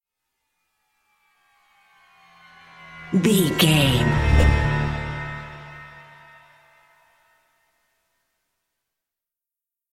Aeolian/Minor
synthesiser
percussion
dark
spooky